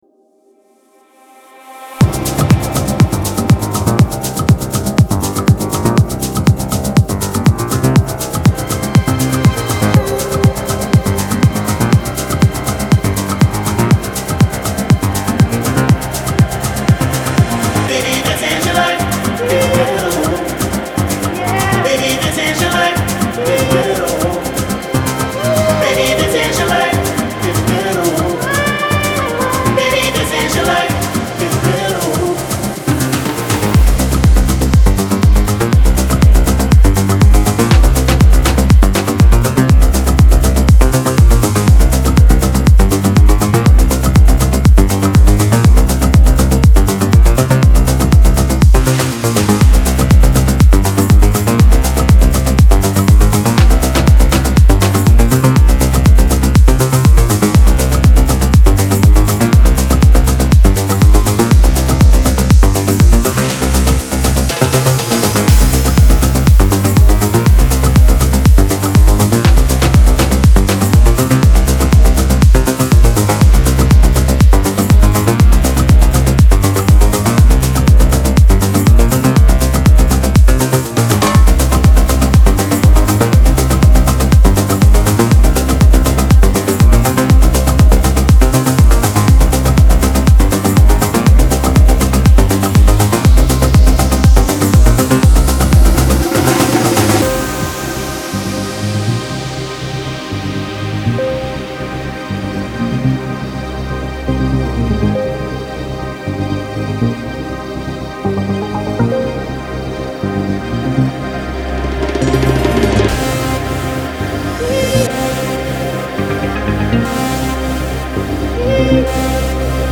дуэт , dance
танцевальная музыка